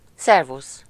Ääntäminen
IPA: [sɑ̃.te]